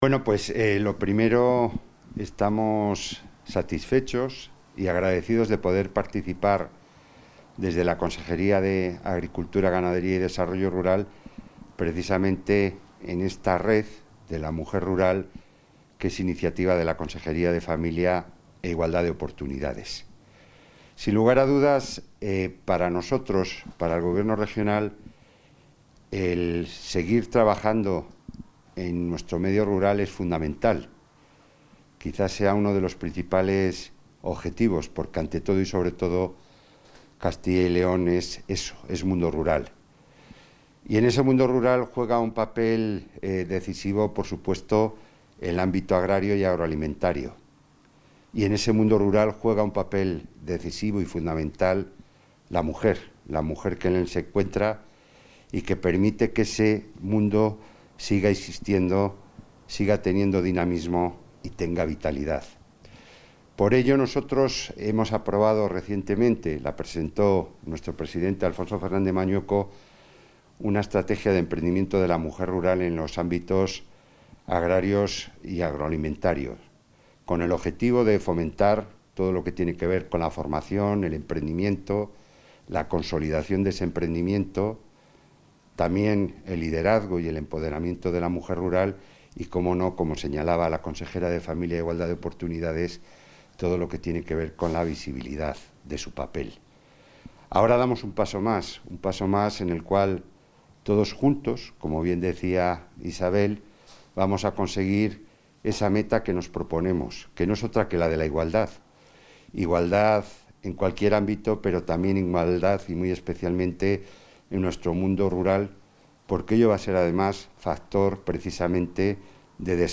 Intervención de la consejera de Familia e Igualdad de Oportunidades.